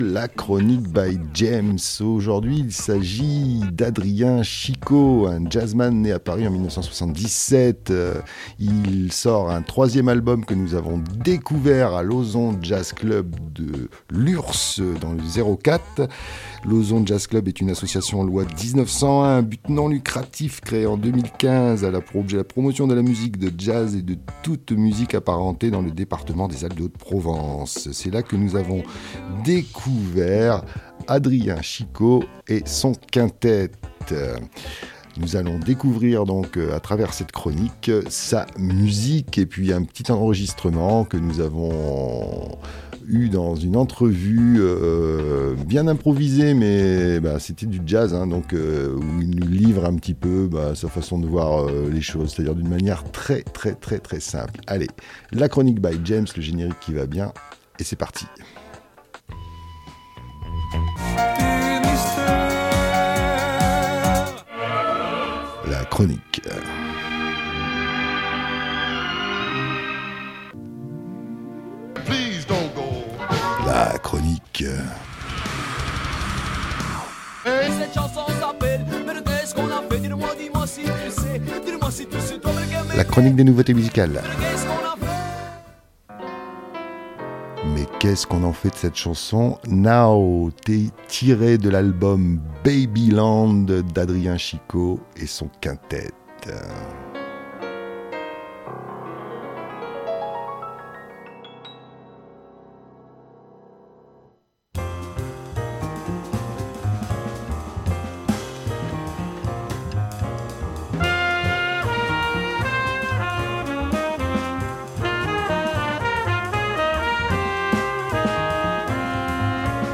Quelques extraits ponctués de commentaires et d'une entrevue avec l'artiste lors de notre rencontre nous offrent un très bon moment à écouter et réécouter...